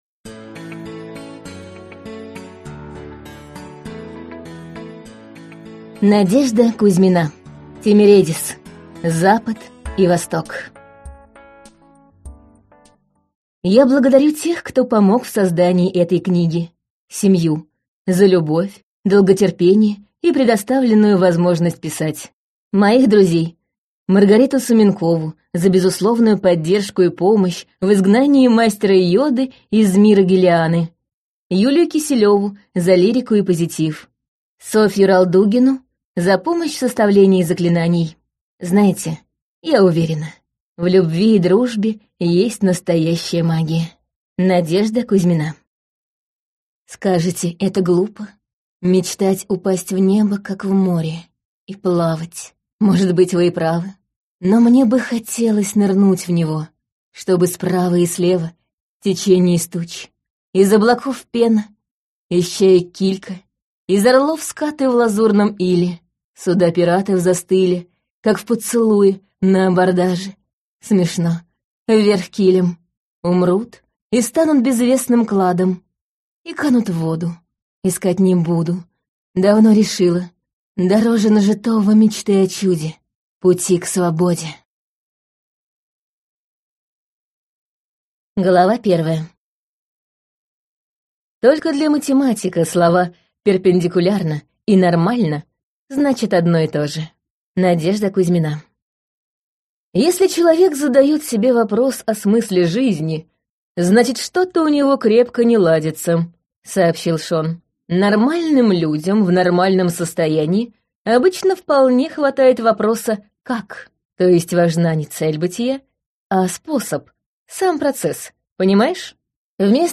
Аудиокнига Запад и Восток | Библиотека аудиокниг
Прослушать и бесплатно скачать фрагмент аудиокниги